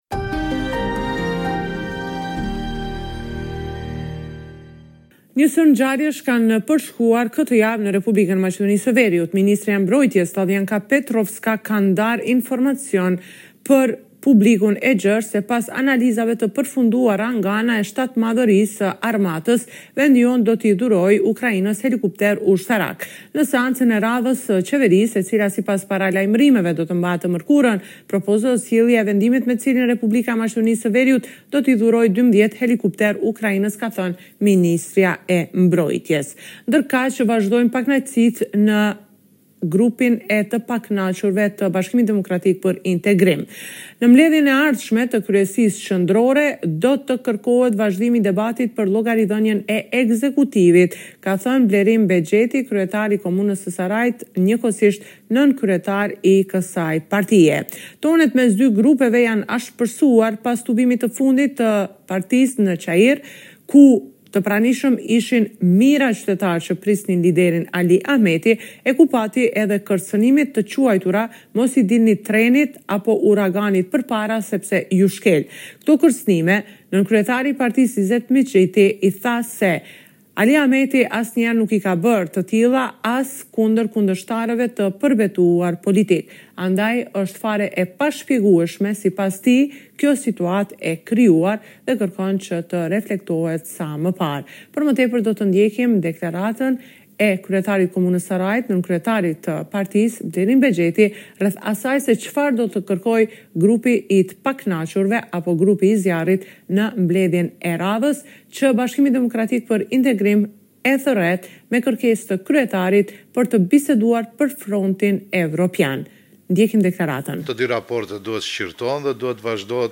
Raporti me të rejat më të fundit nga Maqedonia e Veriut.